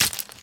candy_break.mp3